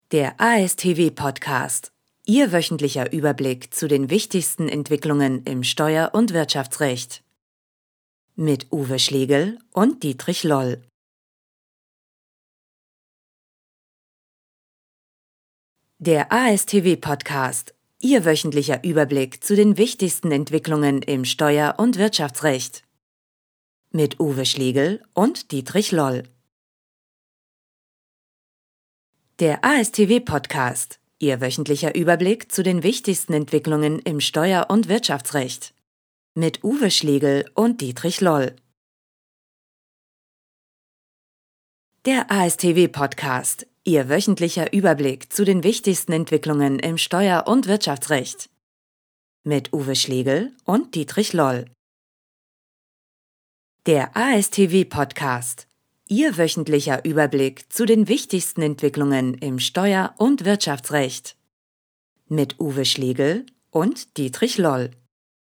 Mittel minus (25-45)
Commercial (Werbung)